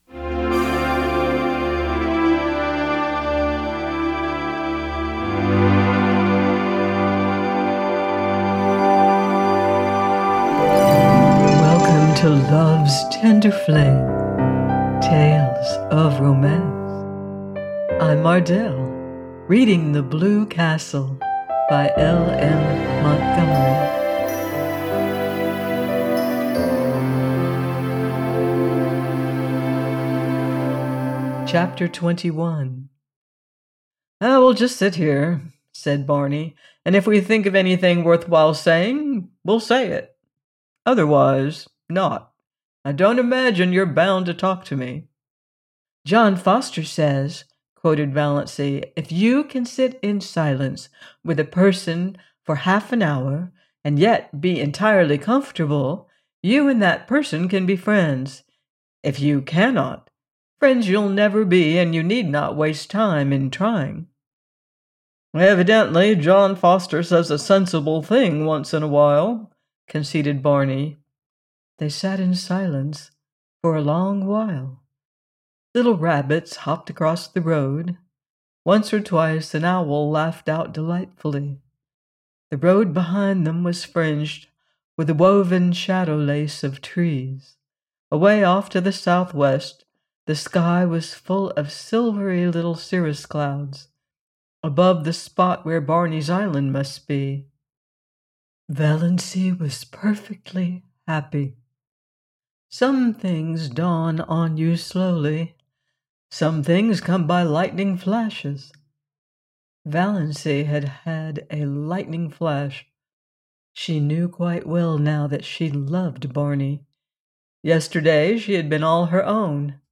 The Blue Castle by L.M. Montgomery - audiobook